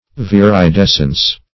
Viridescence \Vir`i*des"cence\, n.